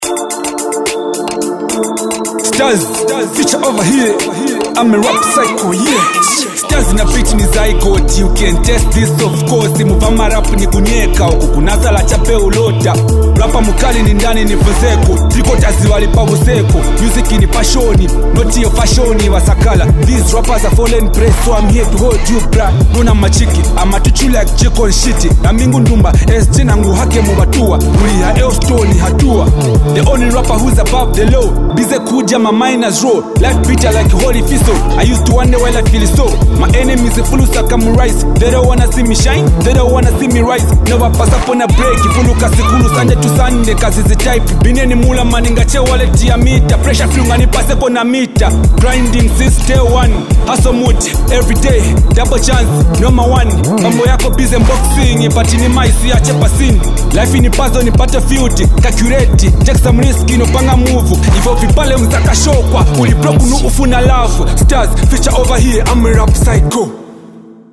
catchy rap song